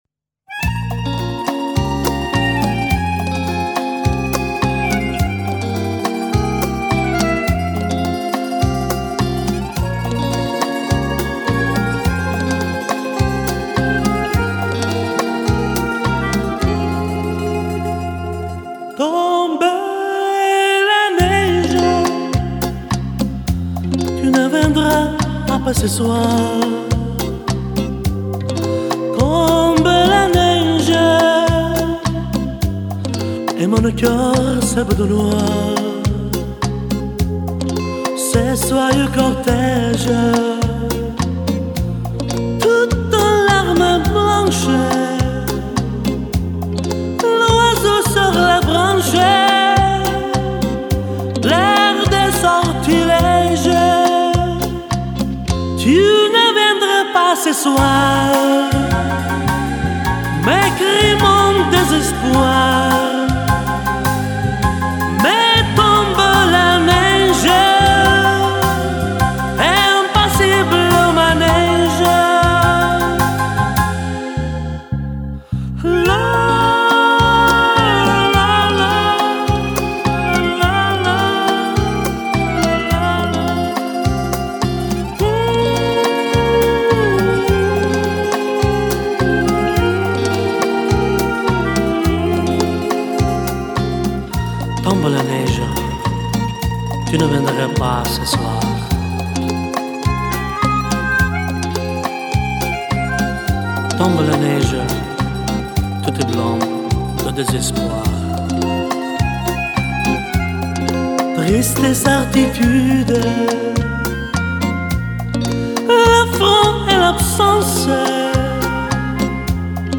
И вокал интересный, и инструментал очень приятный!